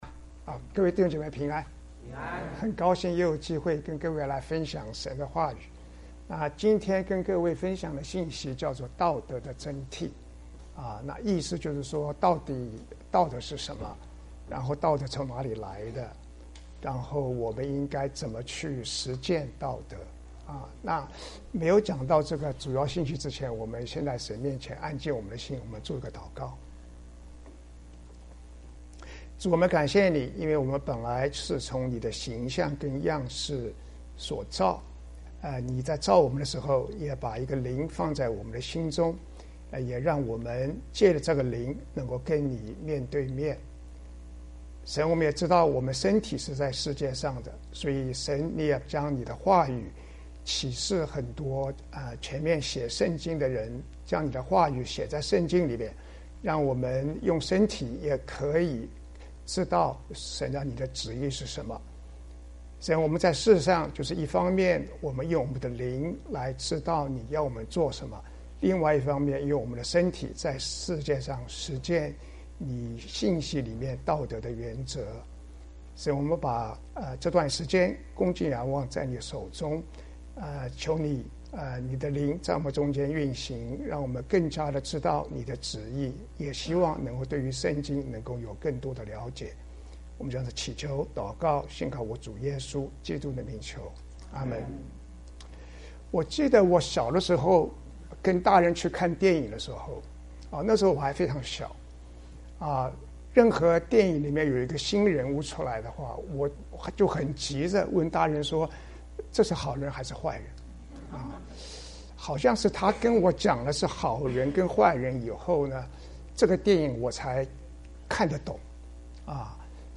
弟兄 應用經文: 彌迦書 6 章 8 節